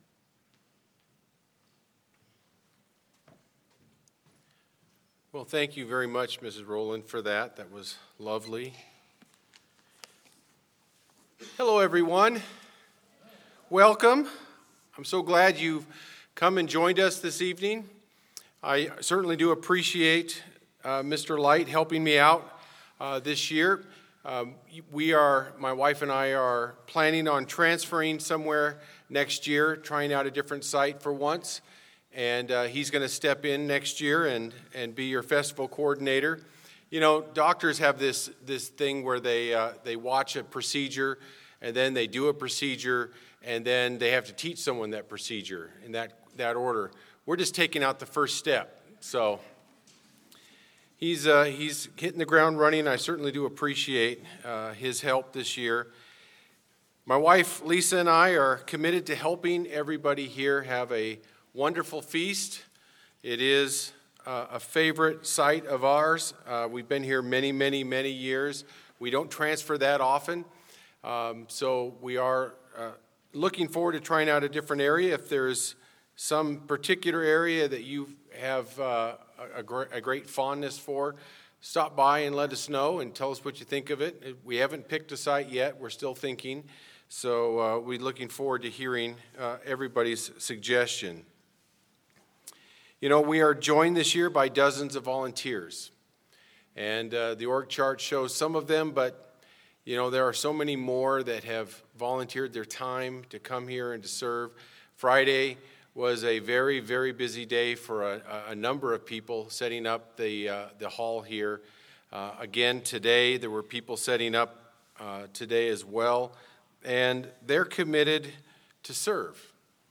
This sermon was given at the Bend-Redmond, Oregon 2019 Feast site.